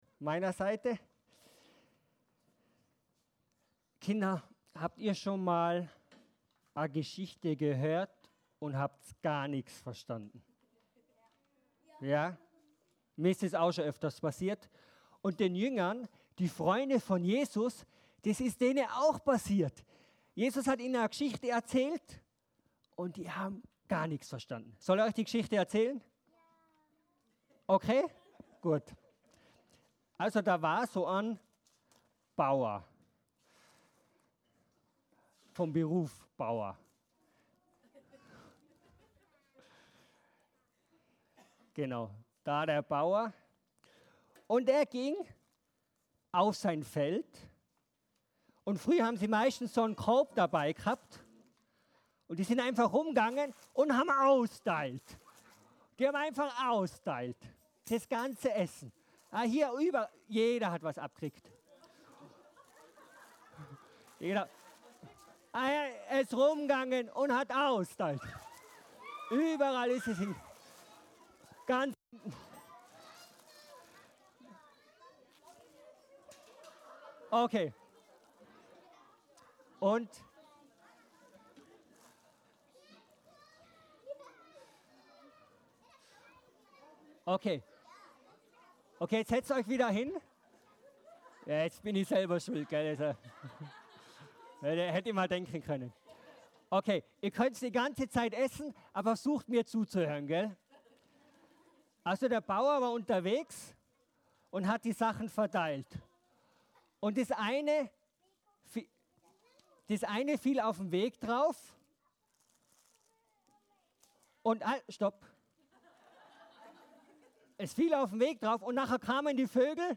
Predigt über Vierfacher Acker